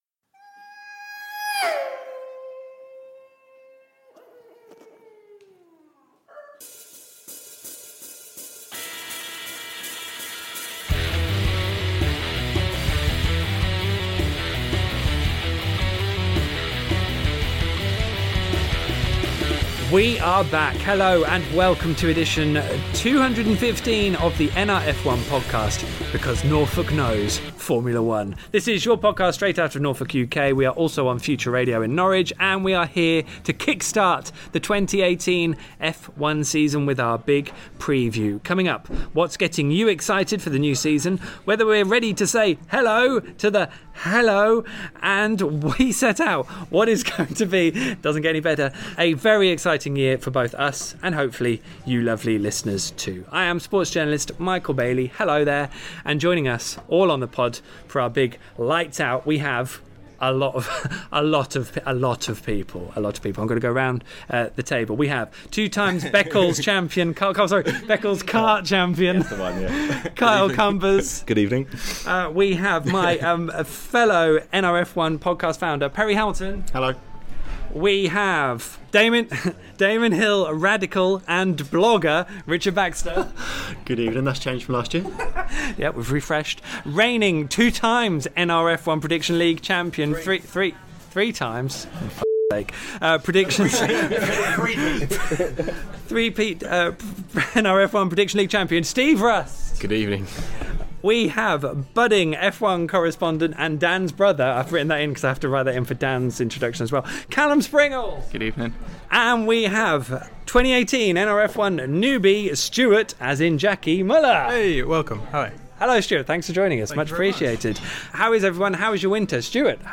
a bumper crew bring you edition 215 of The NR F1 Podcast, ready to preview all the action that lays ahead of the 2018 season.